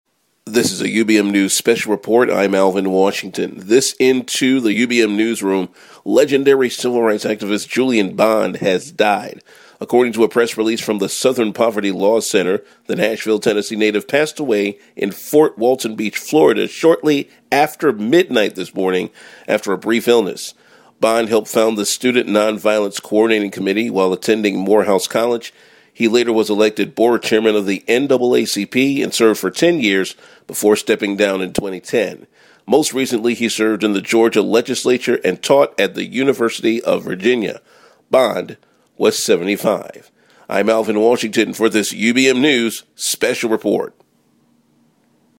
BREAKING NEWS - BOND PASSES